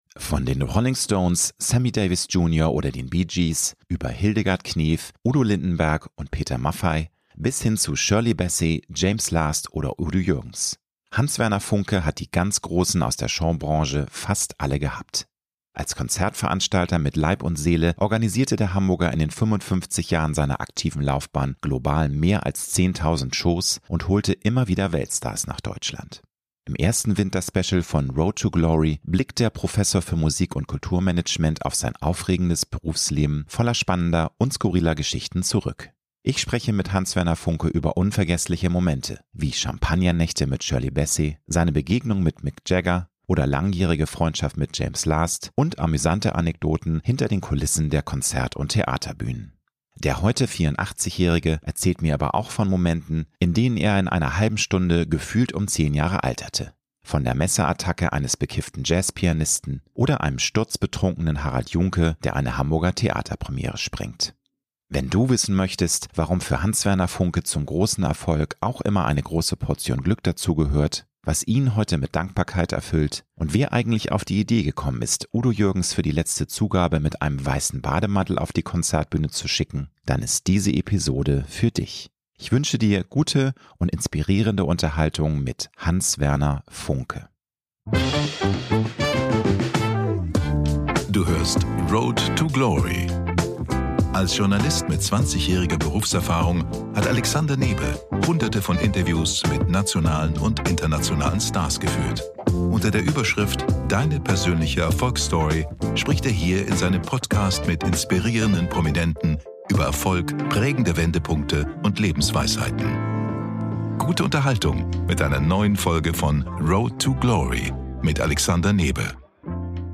~ Road to Glory - Promi-Talk